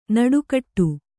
♪ naḍukaṭṭu